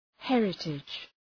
Προφορά
{‘herıtıdʒ}